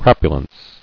[crap·u·lence]